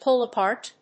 アクセントpúll apárt